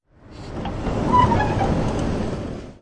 描述：自动门的声音在内部的与人谈话在背景中。它使用Adobe Audition CC进行处理，降噪，均衡和压缩。用带有内置麦克风的Zoom H4 Handy Recorder录制。
标签： 环境 自动门系列 环境 室内
声道立体声